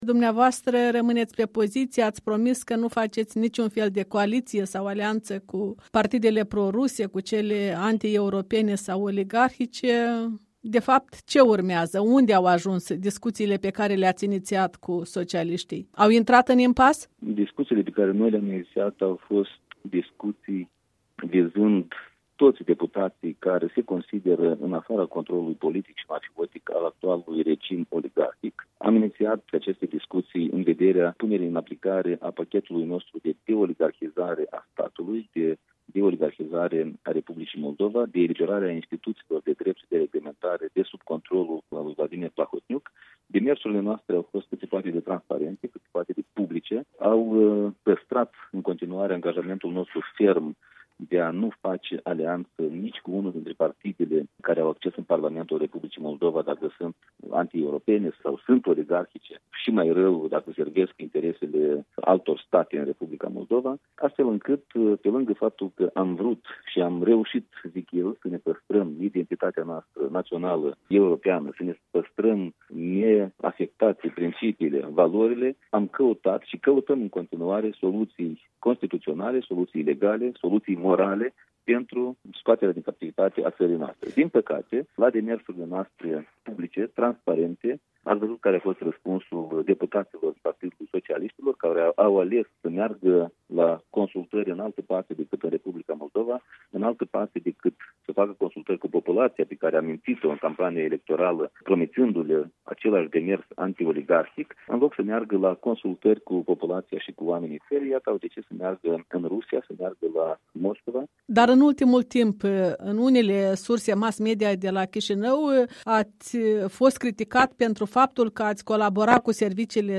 Interviu cu copreședintele Blocului ACUM.
Interviu cu copreședintele Blocului ACUM, Andrei Năstase